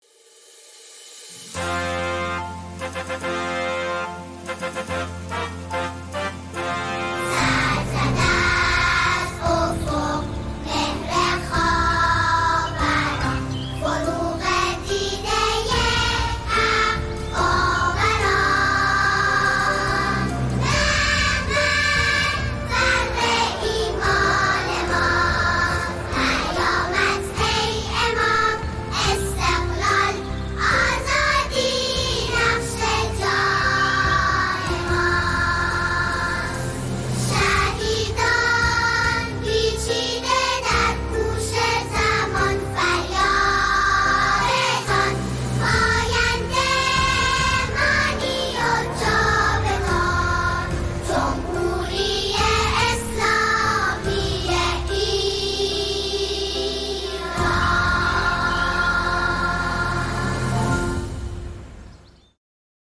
با جمعی از کودکان اجرا شده است
با فضایی پاک و امیدآفرین
ژانر: سرود